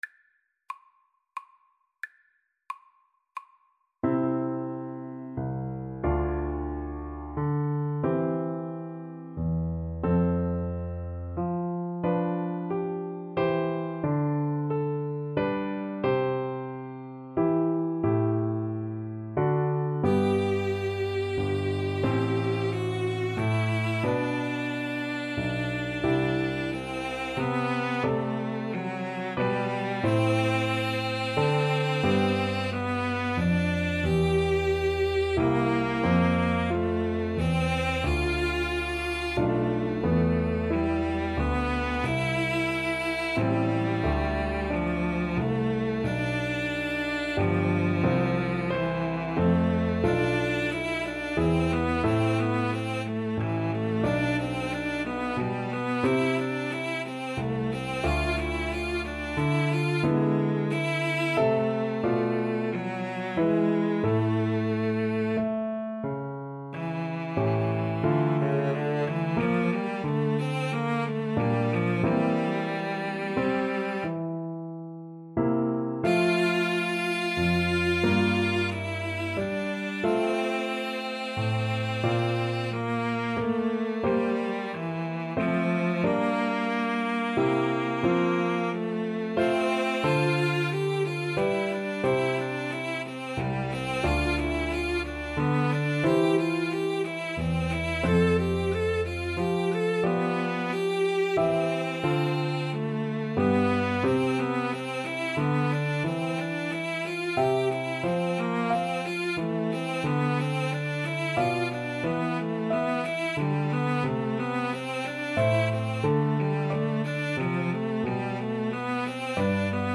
Largo ma non tanto ( = c. 90)
Classical (View more Classical Cello Duet Music)